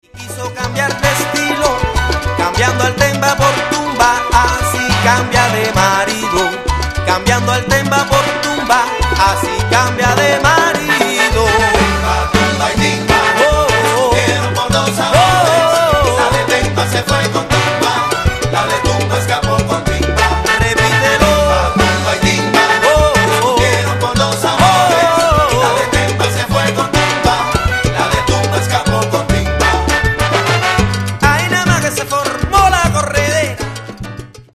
Nouvelle salsa cubaine ou Timba